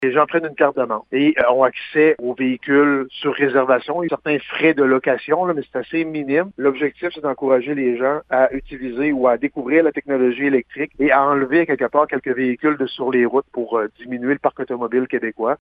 Le maire de Gaspé et président de la RÉGÎM, Daniel Côté, donne un peu plus de précisions quant à ce projet :